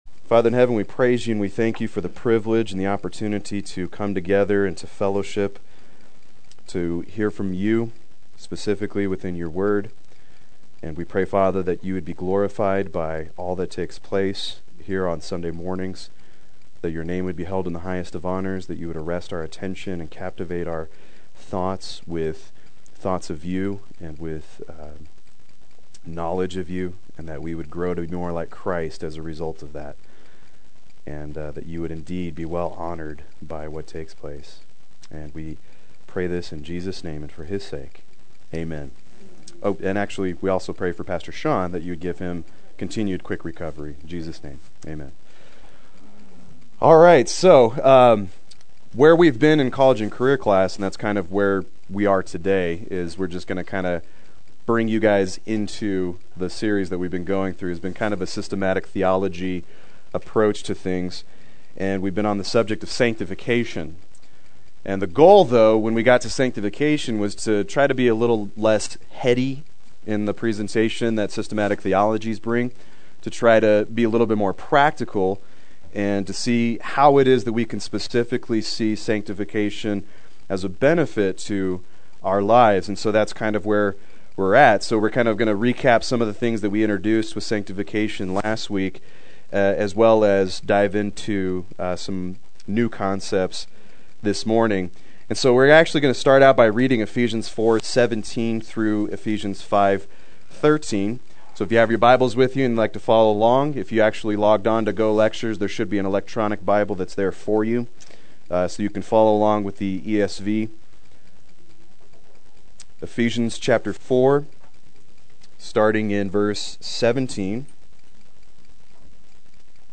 Play Sermon Get HCF Teaching Automatically.
Sanctification Recap Adult Sunday School